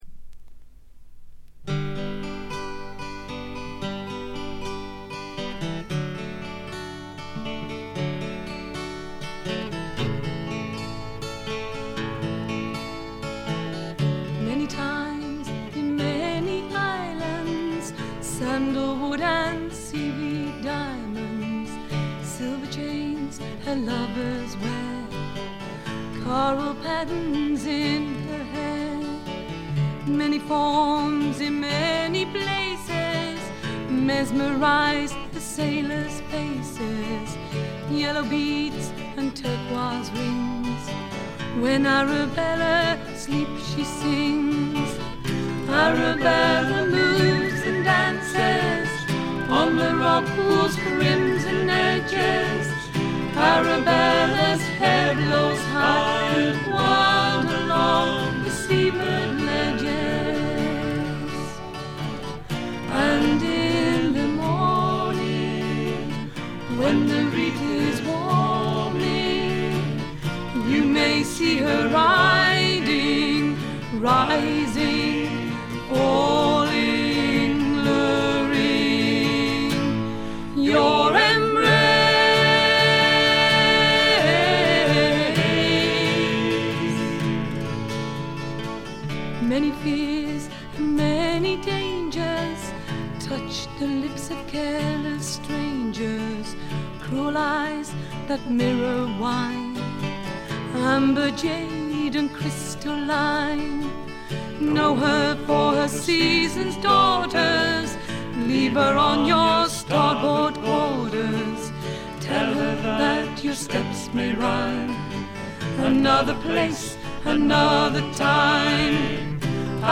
フィメールを含む4人組。
試聴曲は現品からの取り込み音源です。
vocals, concertina, keyboards
acoustic guitar, fiddle, vocals
banjo, whistle, vocals
Recorded at Mid Wales Sound Studio June 1976